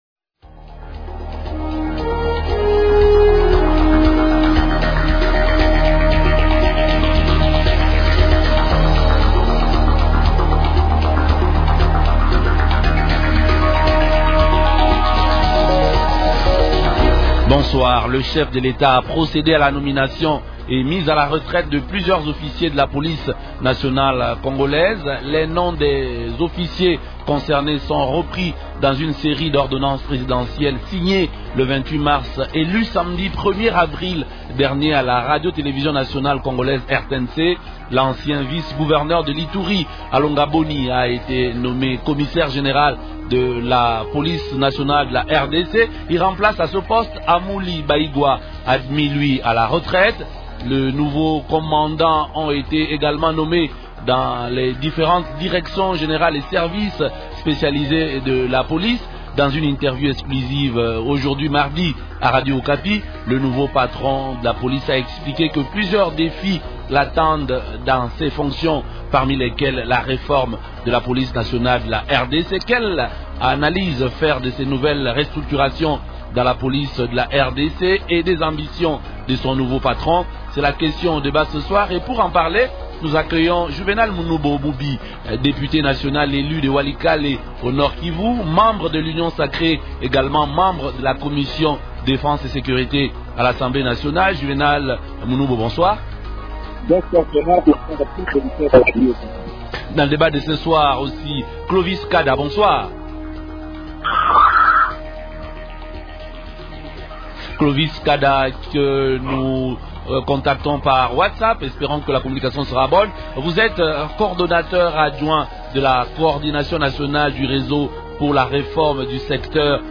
L'actualité politique de ce soir
-Quelle analyse faire de ces nouvelles restructurations dans la police nationale de la RDC ? Invités : - Juvénal Munubo Mubi, député national élu de Walikale au Nord-Kivu.